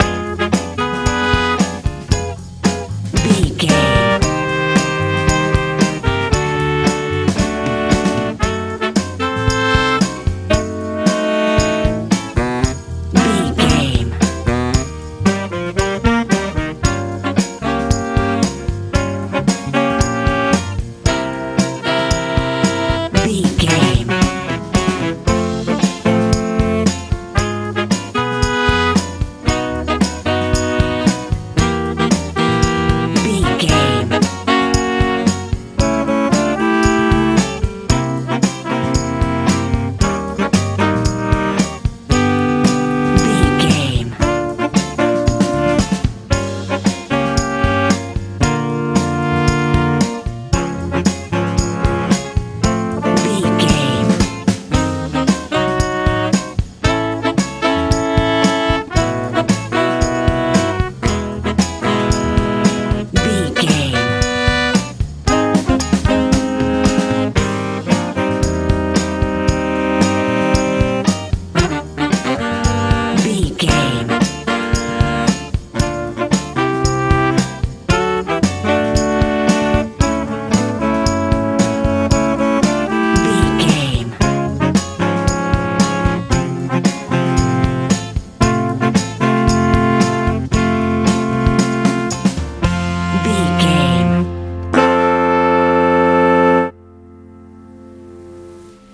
60s soul feel
Ionian/Major
A♭
groovy
funky
electric guitar
electric piano
drums
bass guitar
positive